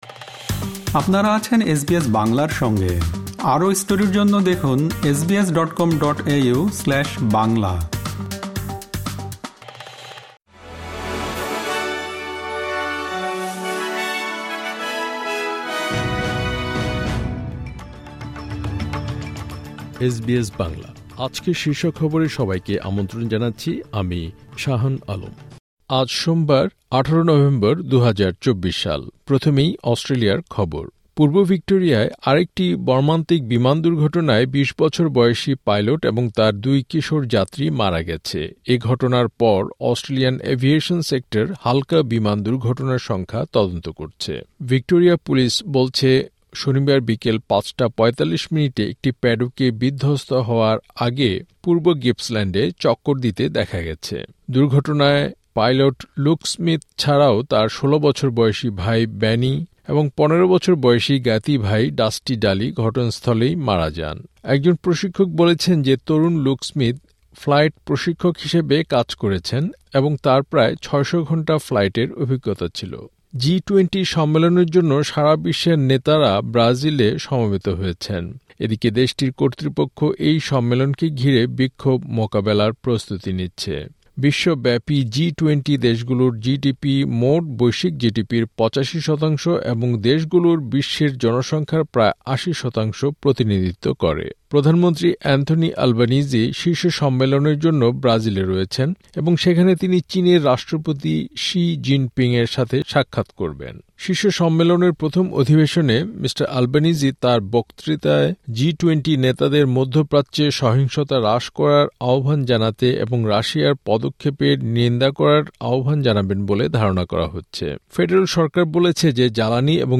এসবিএস বাংলা শীর্ষ খবর: ১৮ নভেম্বর, ২০২৪